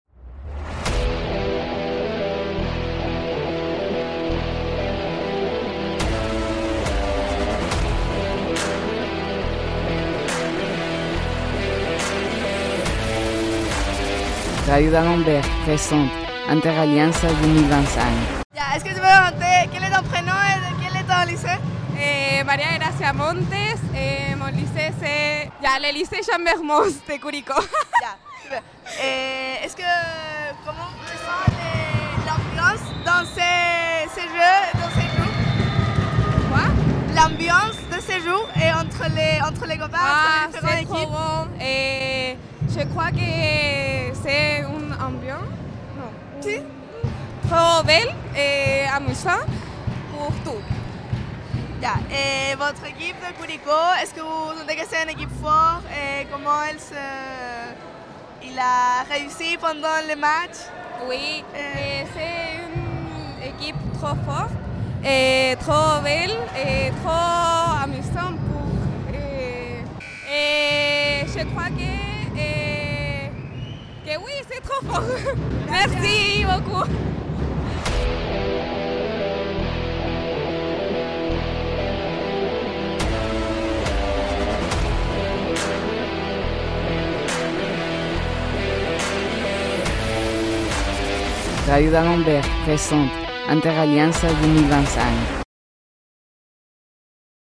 Aujourd'hui plongés au coeur des interalliances 2025 au Lycée Jean d'Alembert, nous avons interviewé quelques acteurs de cet évènement marquant pour la communauté.